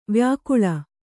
♪ vyākuḷa